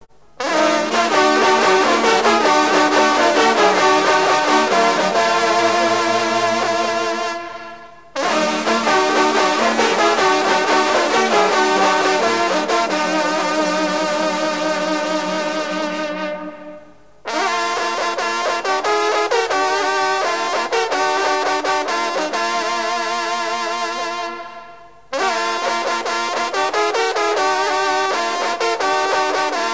circonstance : rencontre de sonneurs de trompe